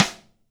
Q DWSN Rim mx ff.WAV